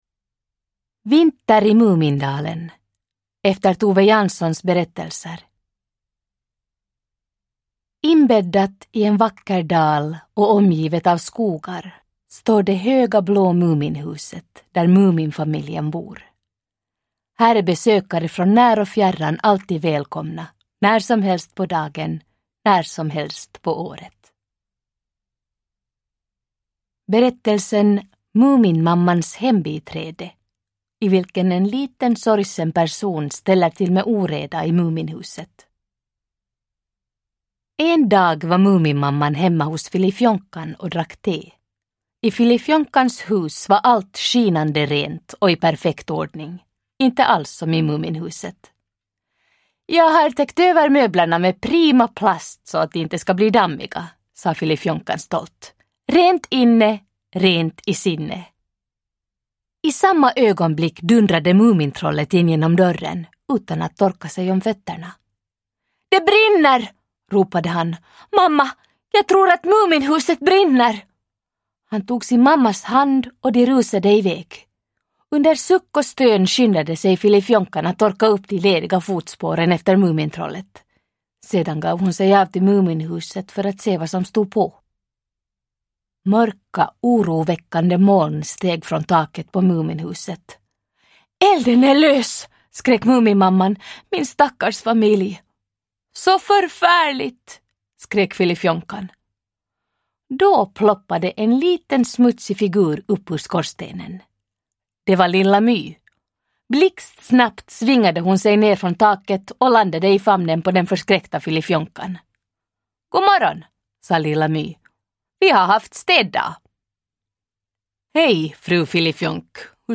Vinter i Mumindalen – Ljudbok – Laddas ner
Uppläsare: Alma Pöysti